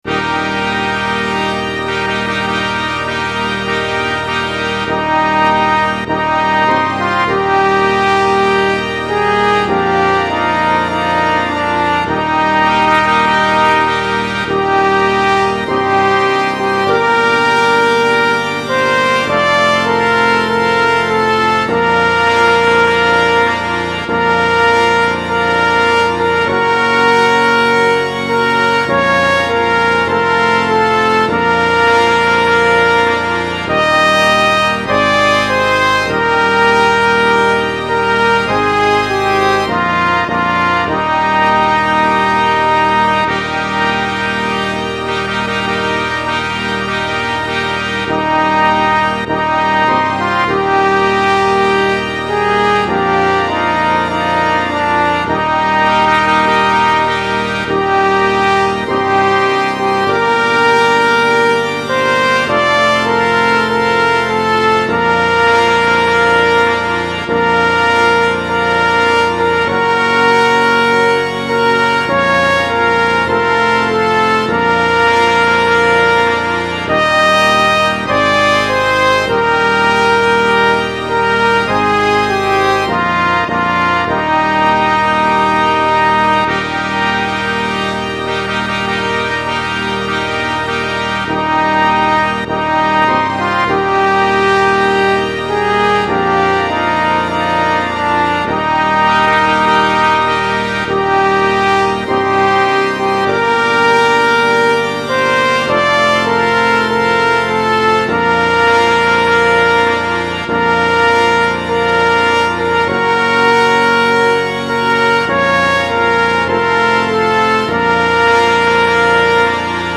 I had terrible trouble getting this one going so I apologise if the backings are less than stellar. I had several goes in several styles so take your pick of these three.